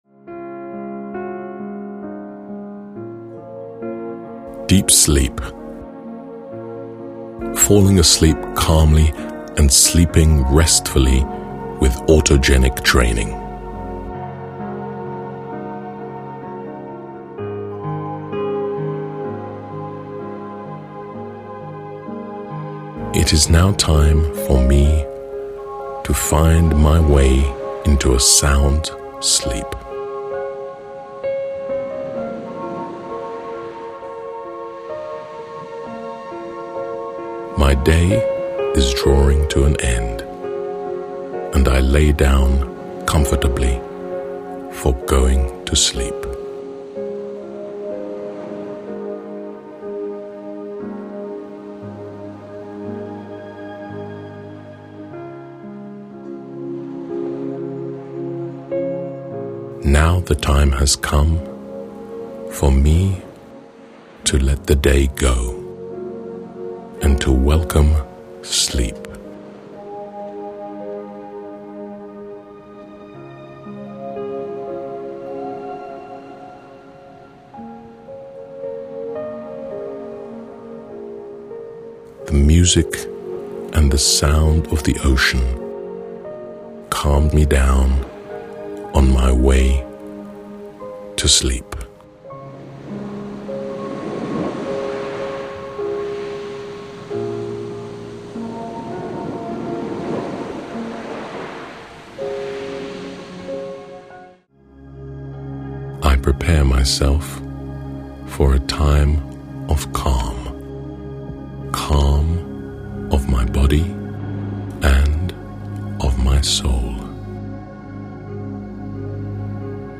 Buy audiobook:
The nature sounds (crashing waves, a babbling brook) and music are carefully put together based on the latest research in sound design for relaxation purposes. They take effect in a soothing and stimulating way. The pitch is set to the natural standard A of 432 Hz.
deep_sleep_with_autogenic_training.mp3